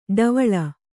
♪ ḍavaḷa